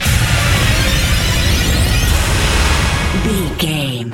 Short musical SFX for videos and games.,
Sound Effects
Epic / Action
Fast paced
In-crescendo
Thriller
Uplifting
Ionian/Major
aggressive
bright
futuristic
industrial
intense
driving
bouncy
dark
energetic
funky
heavy
mechanical